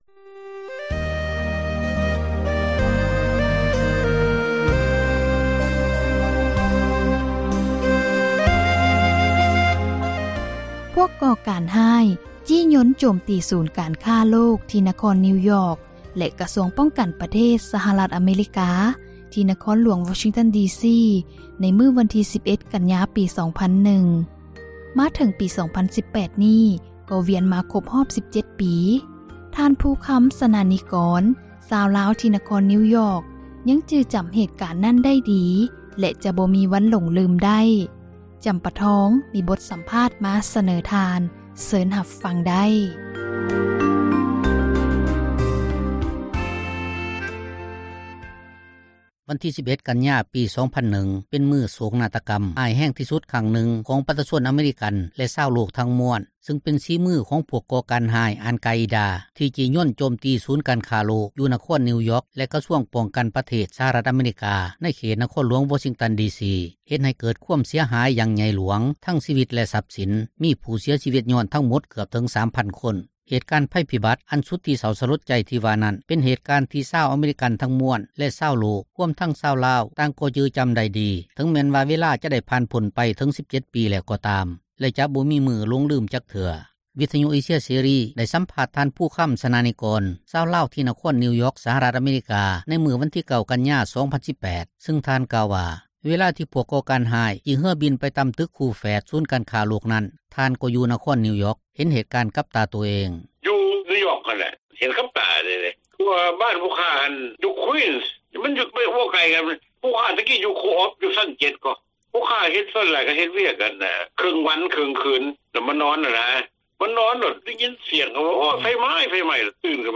ມີບົດສໍາພາດ ມາສເນີທ່ານ ເຊີນ ຮັບຟັງໄດ້...